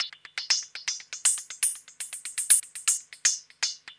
1 channel
Perc03.mp3